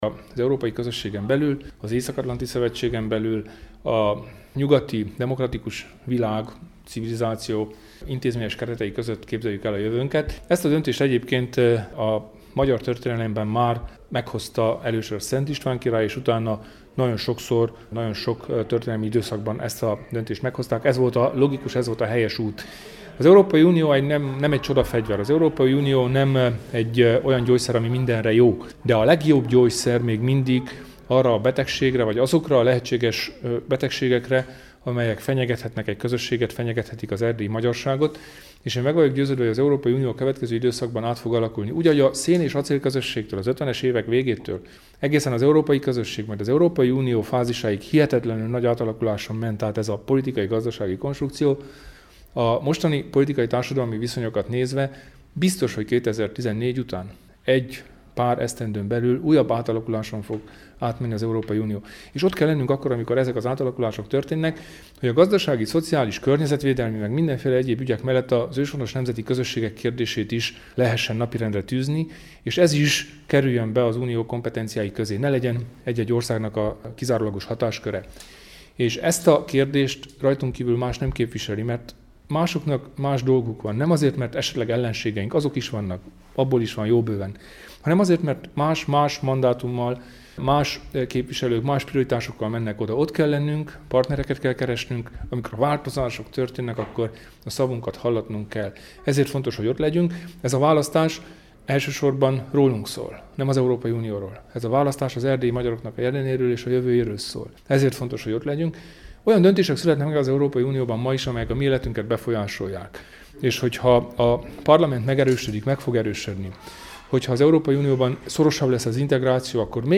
A nagyszínházban tartott pénteki ünnepi előadást megelőző beszédében kiemelte, hogy azért is fontos a május 25-i választáson minél nagyobb számban részt venni, hogy az RMDSZ minél hatékonyabban képviselhesse az őshonos nemzeti közösségek érdekeit az európai parlamentben. Előtte újságíróknak foglalta össze az RMDSZ választási üzenetét.